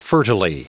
Prononciation du mot fertilely en anglais (fichier audio)
Prononciation du mot : fertilely